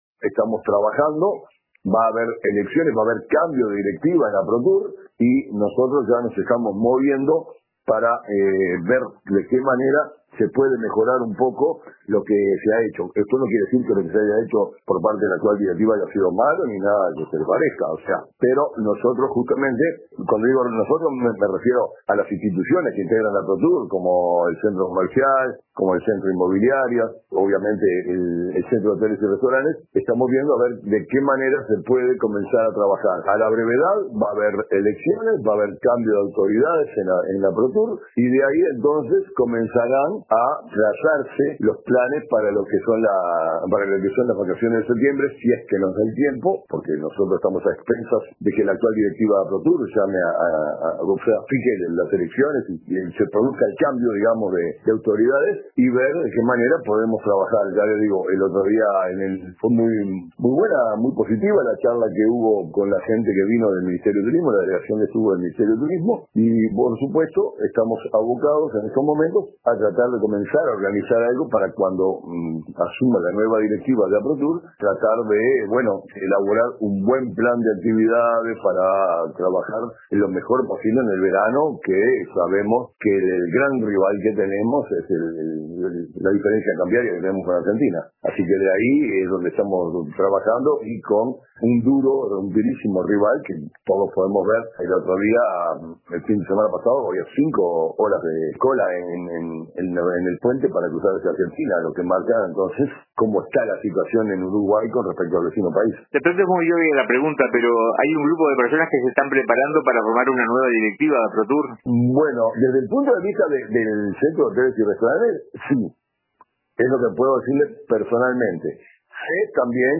En una entrevista con RADIO RBC